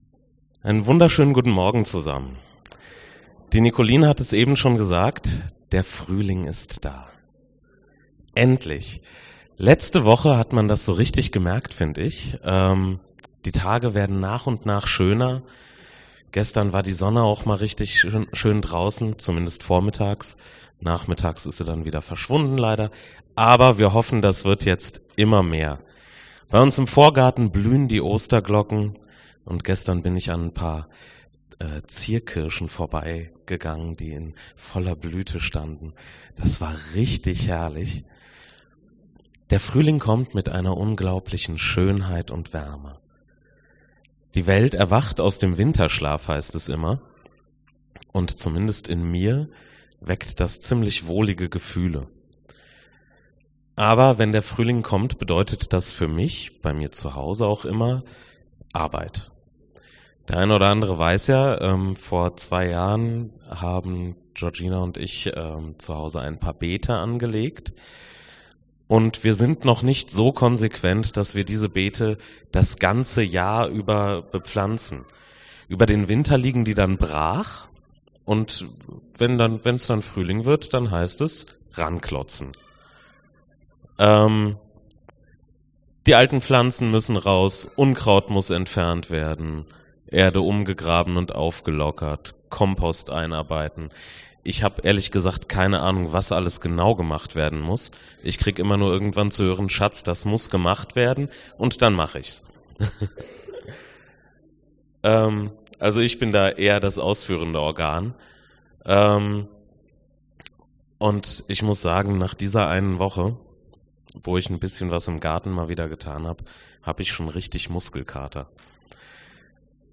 Bibelstelle zur Predigt: 2. Korinther 9, 6-11